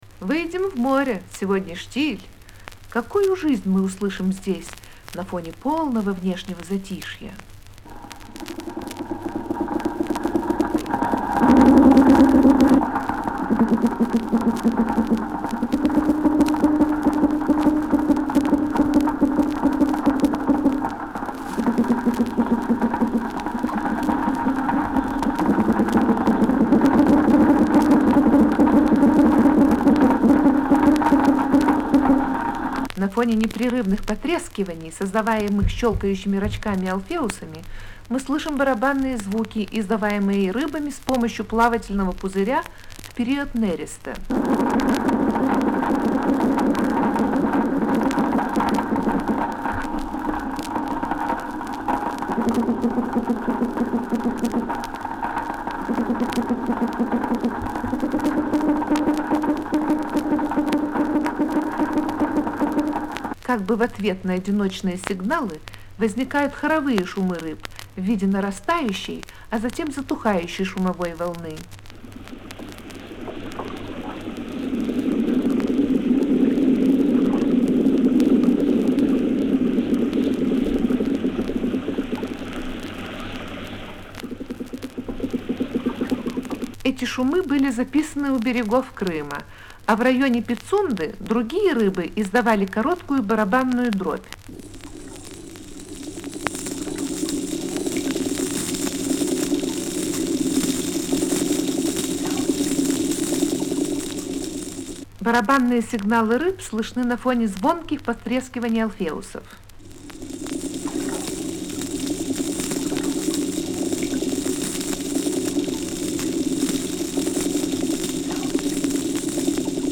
Голоса рыб.